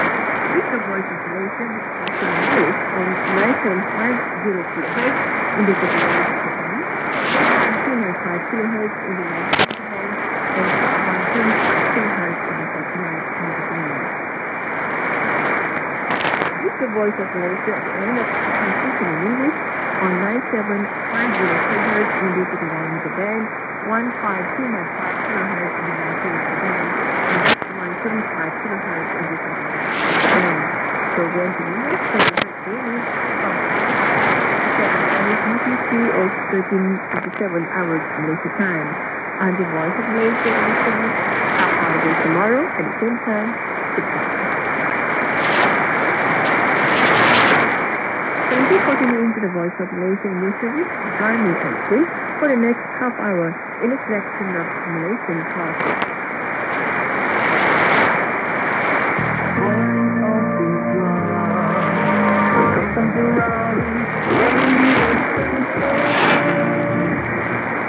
ID: identification announcement
ST: signature tune/jingle